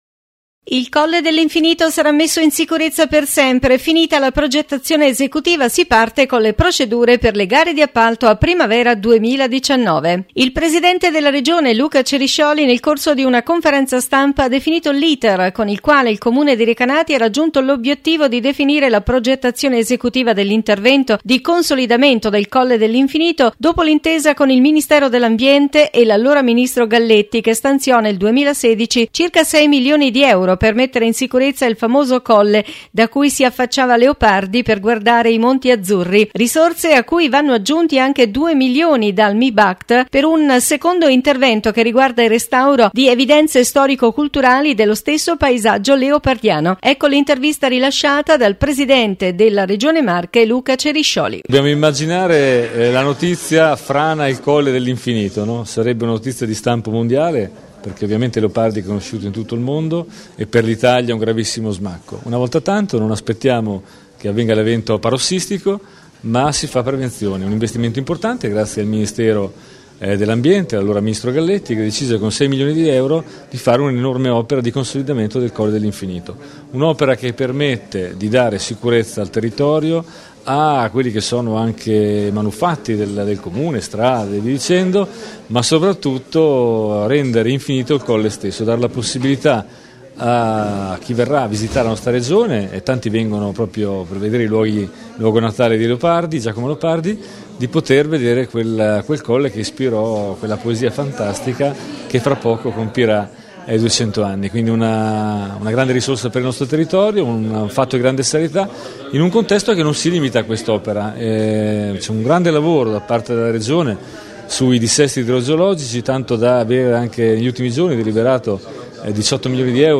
Intervista Luca Ceriscioli – Presidente Regione Marche